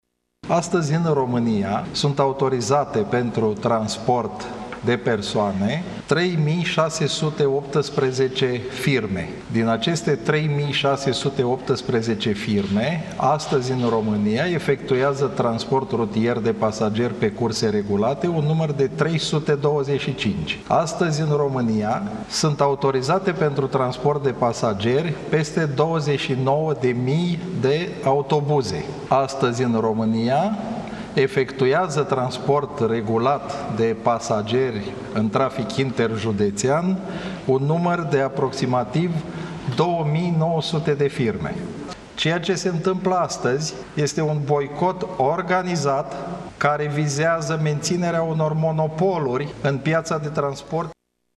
Ministrul transporturilor, Lucian Șova, spune că ceea ce s-a întâmplat astăzi este un boicot organizat pentru menținerea unor monopoluri în transportul județean: